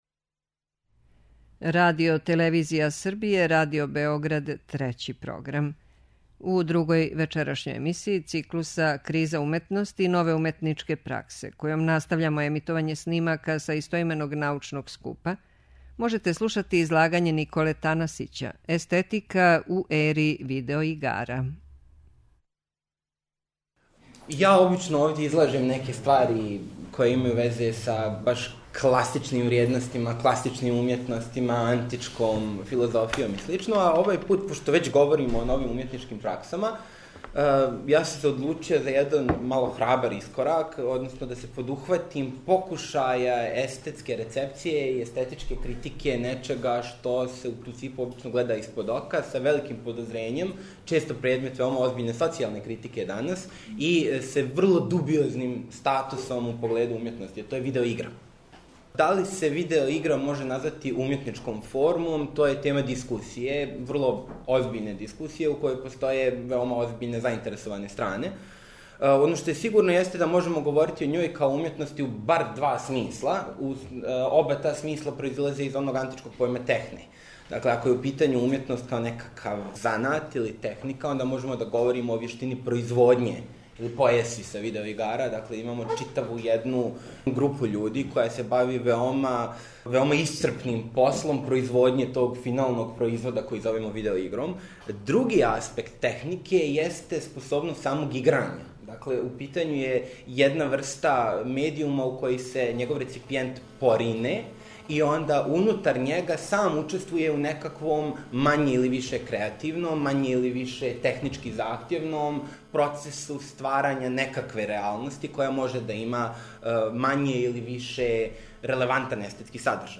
У две емисије, којима настављамо циклус КРИЗА УМЕТНОСТИ И НОВЕ УМЕТНИЧКЕ ПРАКСЕ, можете пратити снимке излагања са истоименог научног скупа одржаног средином децембра у организацији Естетичког друштва Србије.